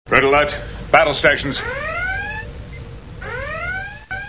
Star Trek Sounds
Englisch Captain Picard befiehlt Alarmstufe Rot
redalert.wav